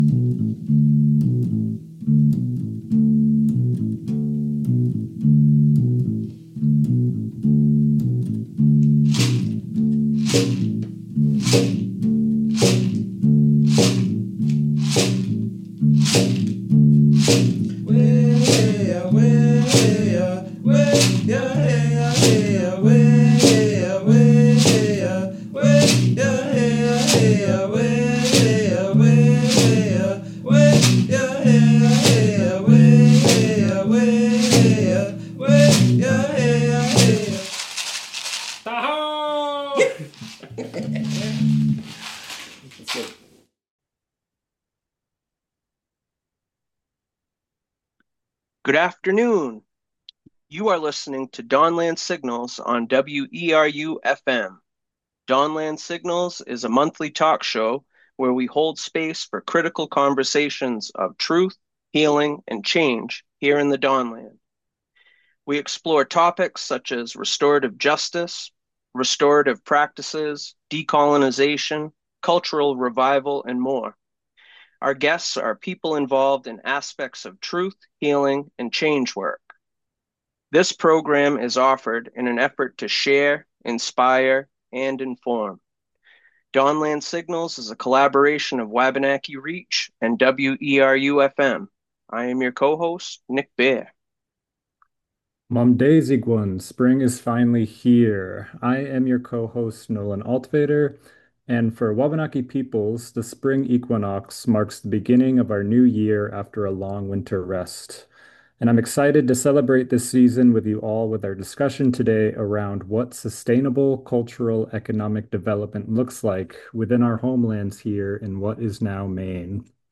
Zoom Recording Technician